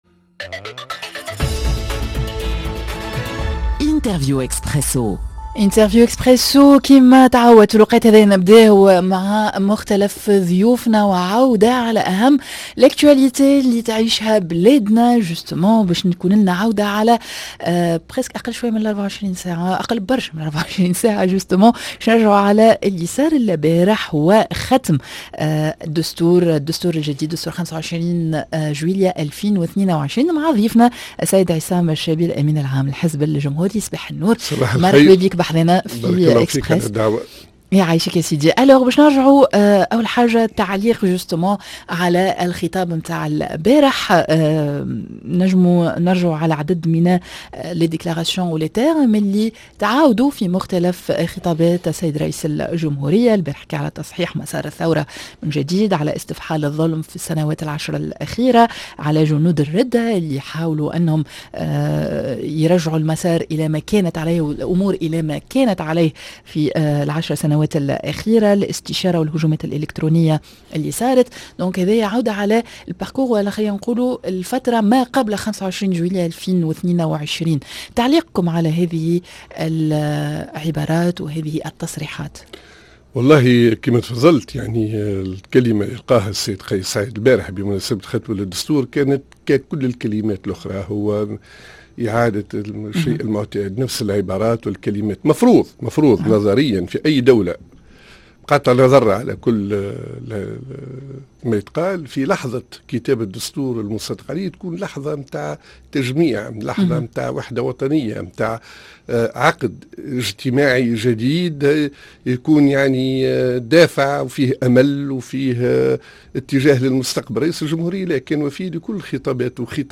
ي دور للمعارضة بعد تثبيت قيس سعيد لمسار 25 جويلية بختم الدستور الجديد ؟ ضيفنا عصام الشابي الأمين العام للحزب الجمهوري